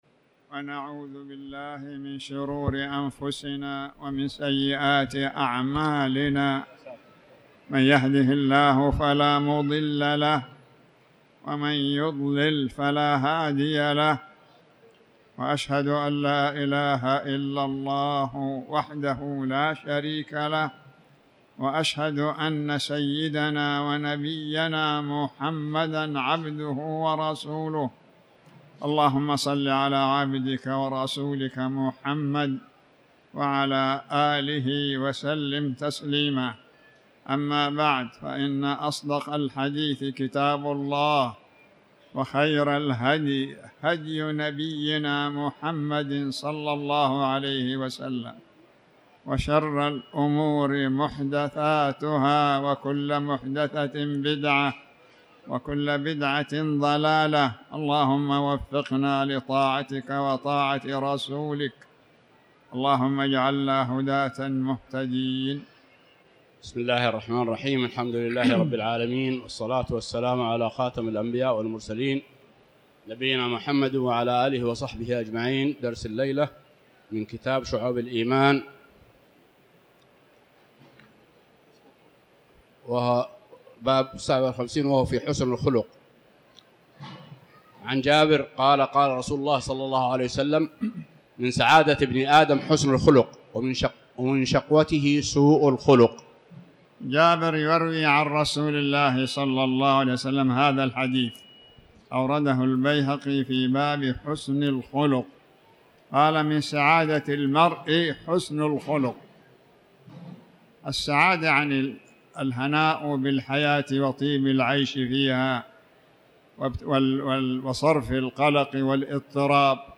تاريخ النشر ٢٧ شوال ١٤٤٠ هـ المكان: المسجد الحرام الشيخ